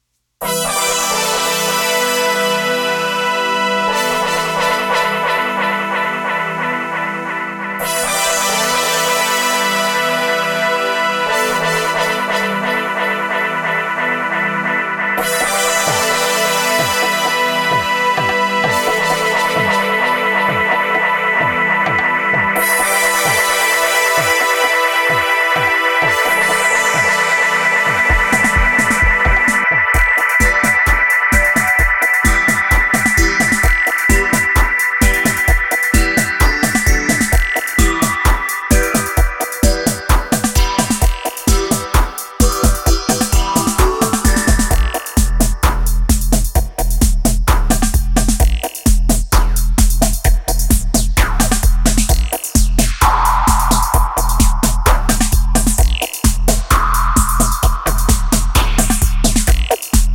produced and mixed solely on analogue gear.
Both tracks are full of heavy and deep vibrations!
Side A2: Dub Mix